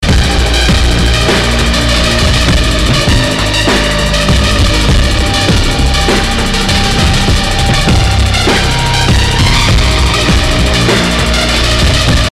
leadmp3.mp3